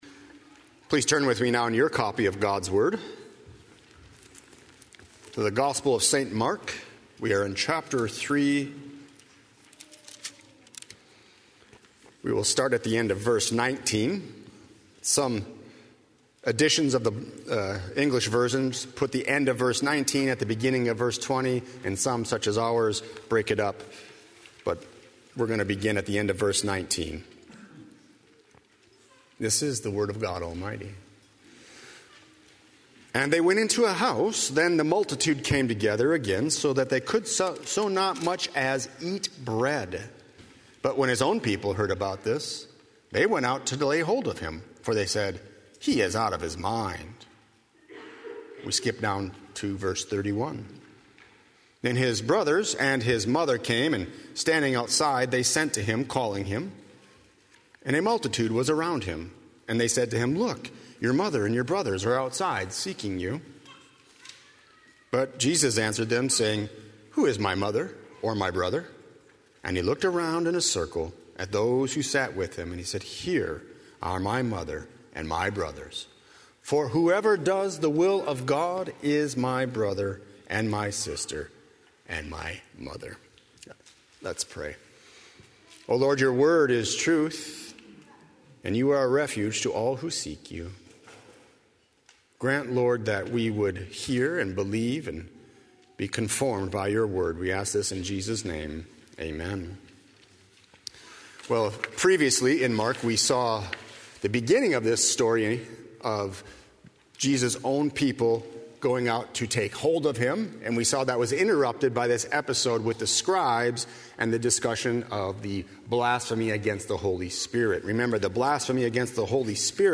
00:00 Download Copy link Sermon Text Believing in Jesus Christ as the eternal Son of God